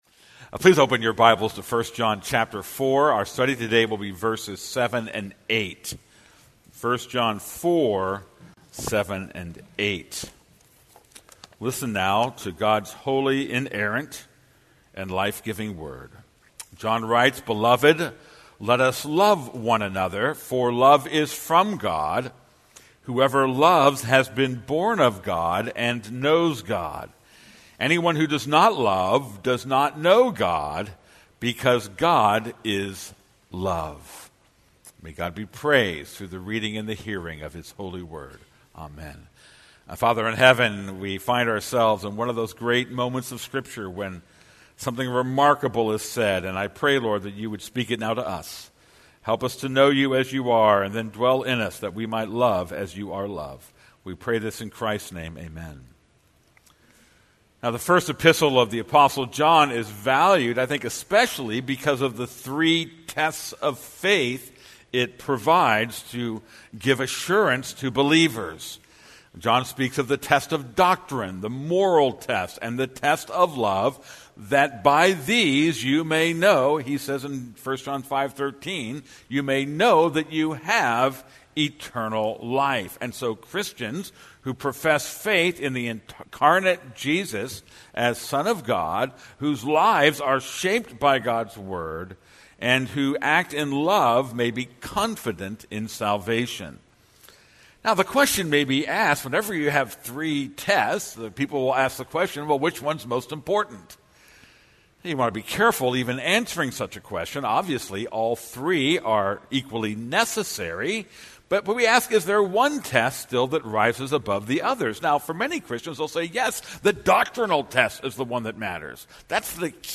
This is a sermon on 1 John 4:7-9.